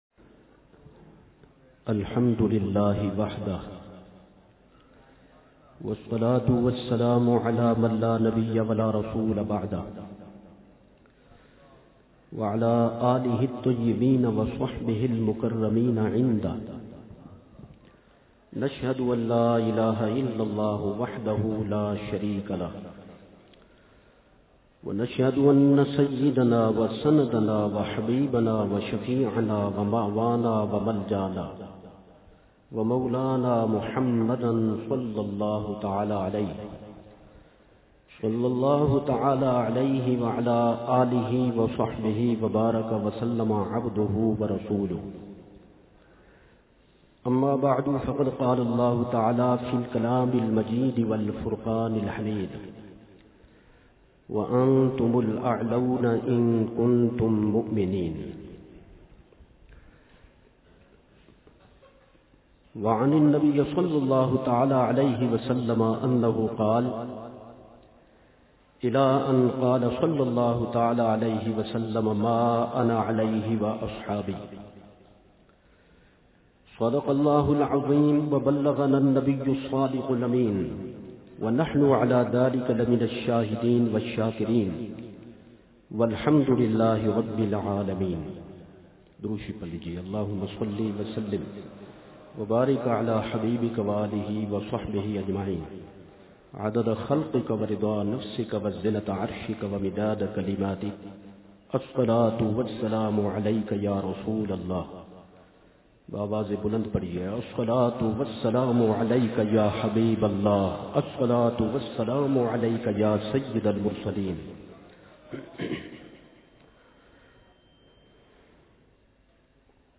بیان بسلسلہ عرسِ اعلیٰ حضرت علیہ رحمہ ۱۴۳۷ھ